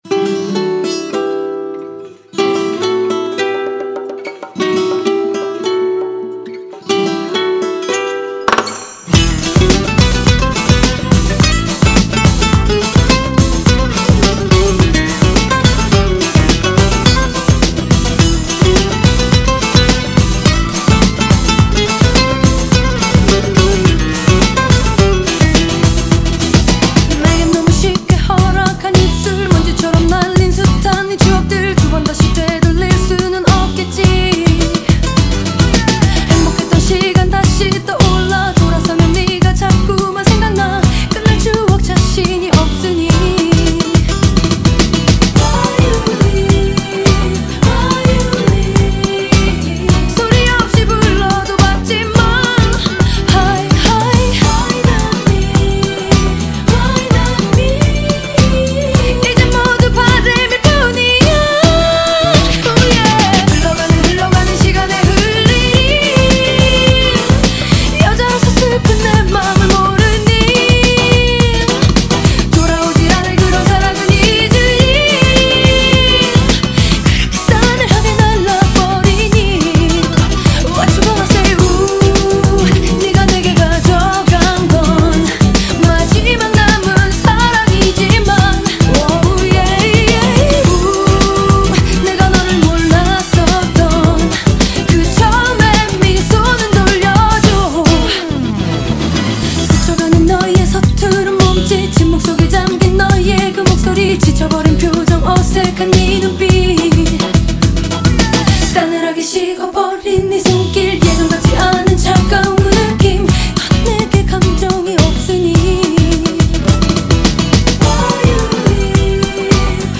Das Demo Lied ist eher einer der sehr ruhigen Lieder.